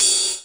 Hathat.wav